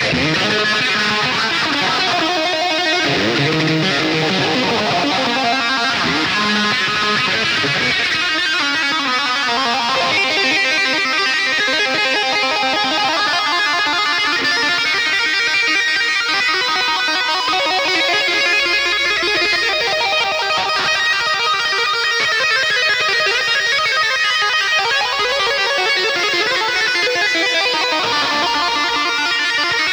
• Hard Rock
instrumental rock guitar solo
drums